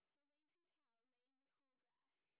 sp17_train_snr30.wav